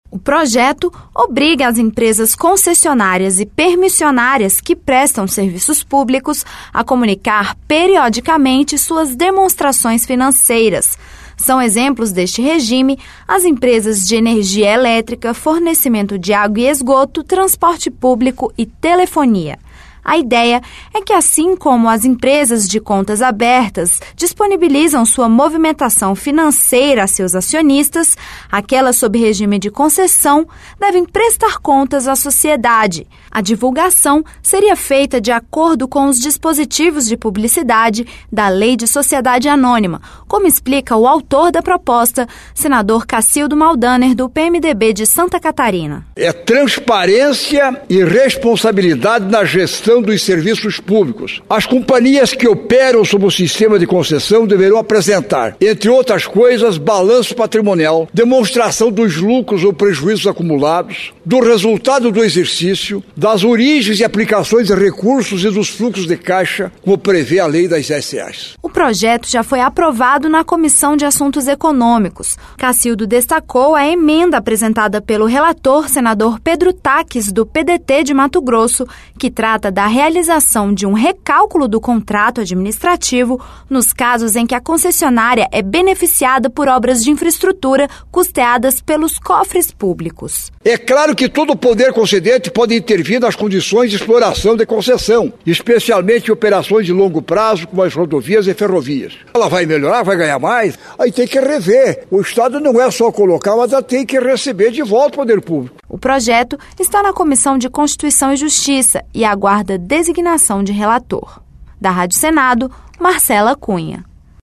A divulgação seria feita de acordo com os dispositivos de publicidade da Lei de Sociedade Anônima, como explica o autor da proposta, senador Casildo Maldaner, do PMDB de Santa Catarina.